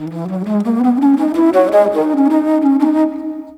Flute 51-08.wav